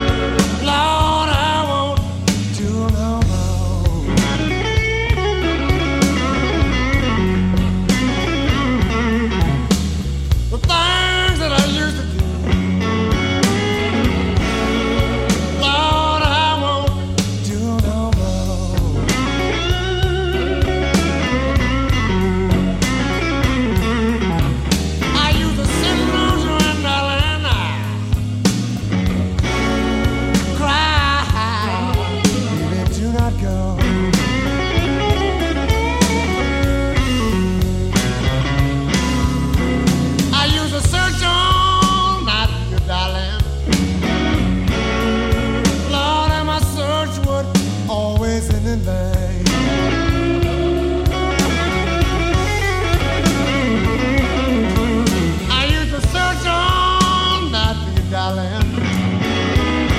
Рок
виртуоз блюзовой гитары